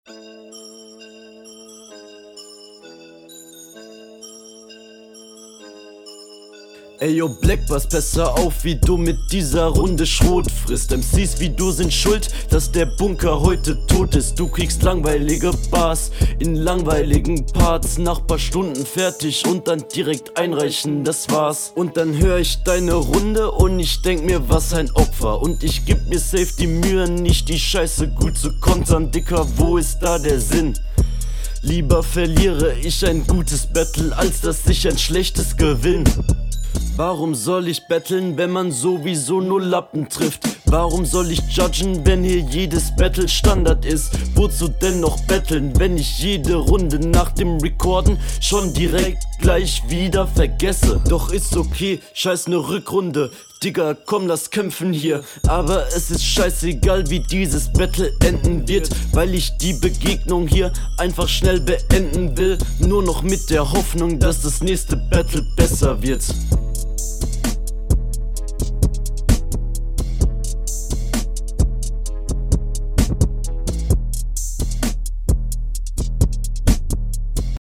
Mehr Rumgeheule als Battlen